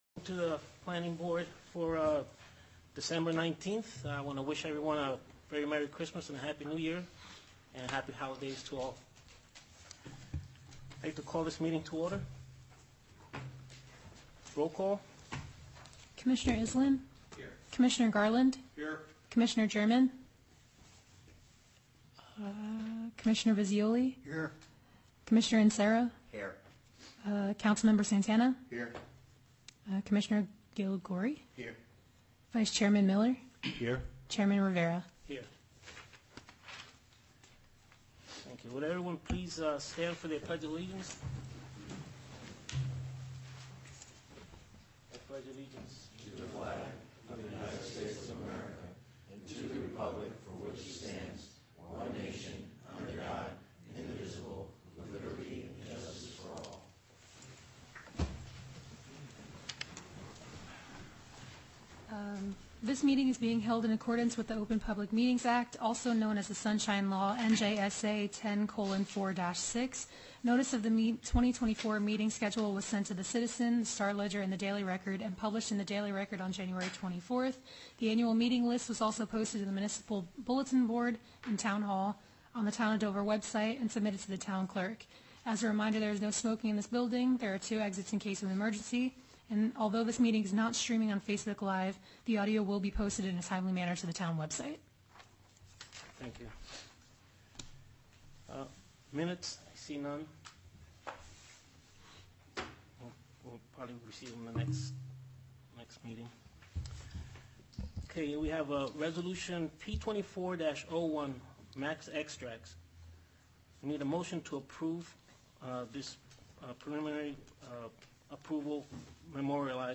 Meeting Information Back To Meeting Schedule Meeting Type : Planning Board Date/Time : Thursday December 19, 2024 @ 7:30 PM Place : Town Hall - 37 No Sussex St. Dover File Listing View Agenda MEETING RECORDING